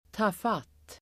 Uttal: [²t'a:fat:]